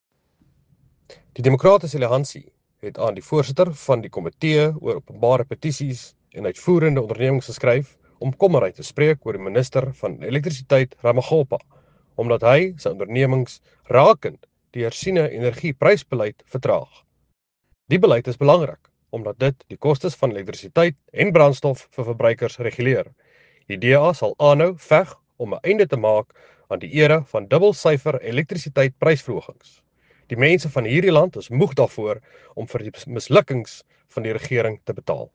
Afrikaans soundbites by Nico Pienaar MP.